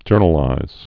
(jûrnə-līz)